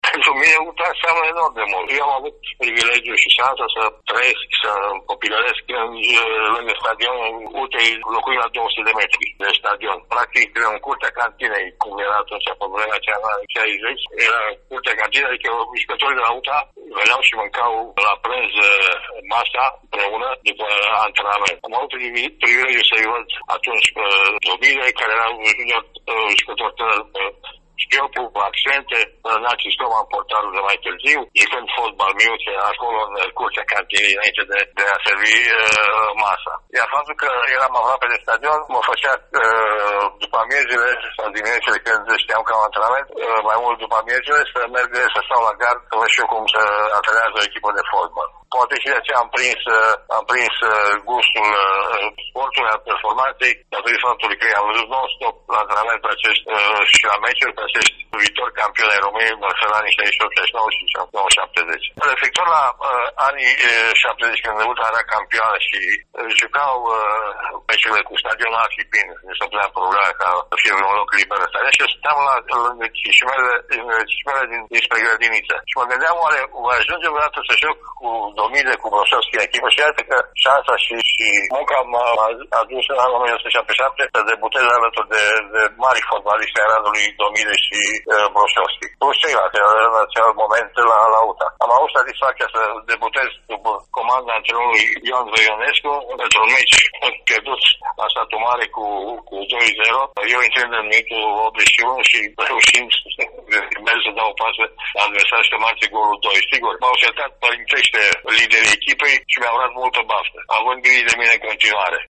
Pe site-ul nostru și în emisiunea Arena Radio de azi, după știrile orei 11, puteți asculta voci ale unor figuri legendare ale clubului de pe Mureș.